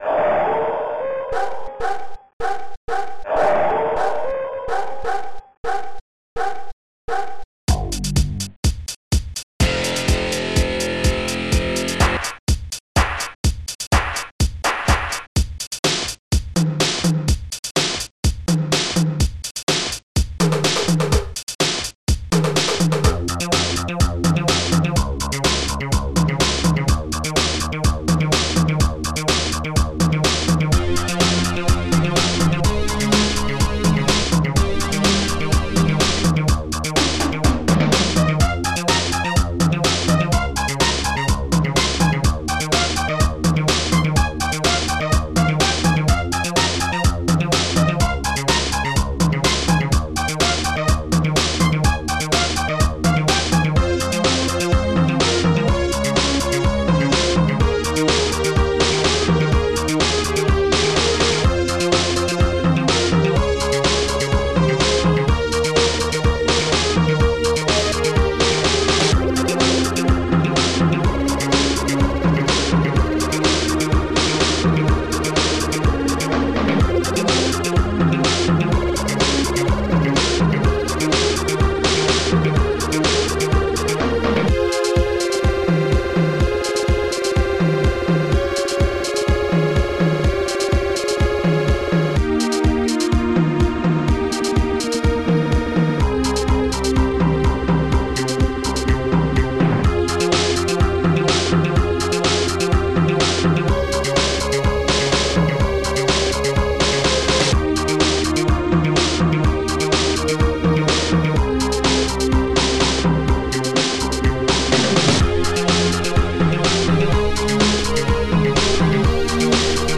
st-01:strings2
st-90:guitar2
st-02:perc-bongo
st-01:hallbrass
st-90:elebass
st-93:leddistguitar